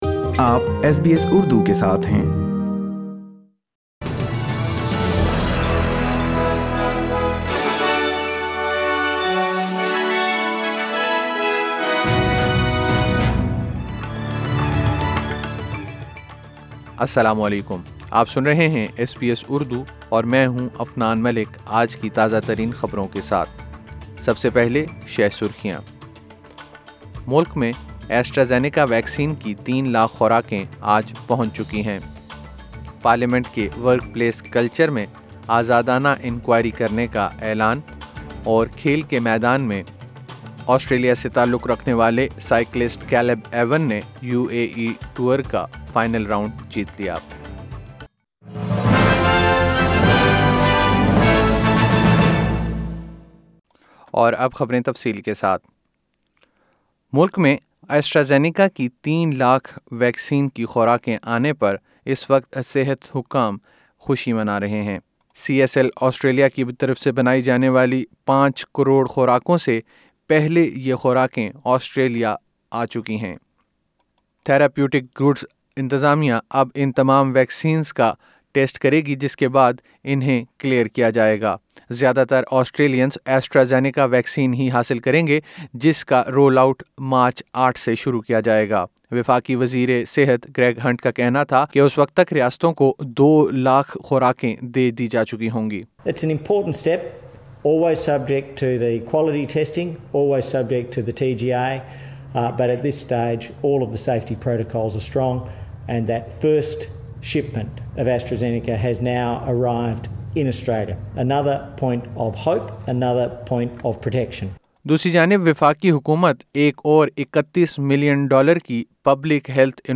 ایس بی ایس اردو خبریں 28 فروری 2021